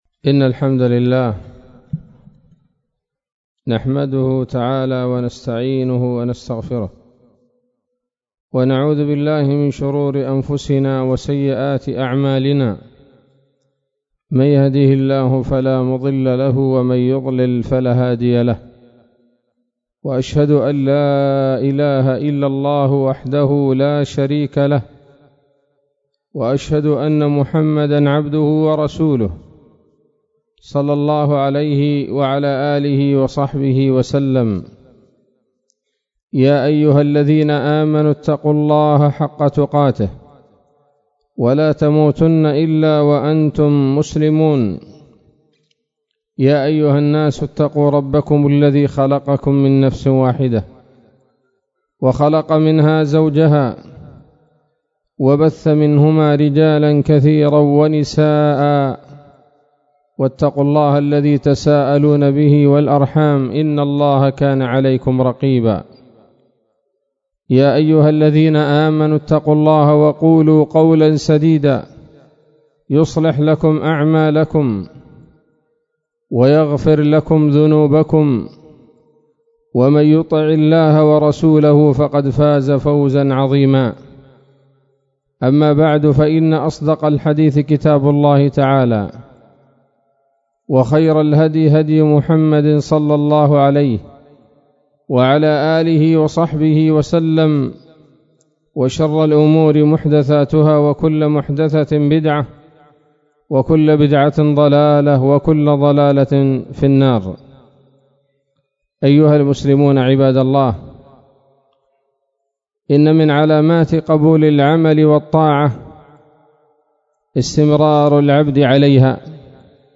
خطبة جمعة بعنوان: (( الوصية بالقرآن )) 4 شوال 1440 هـ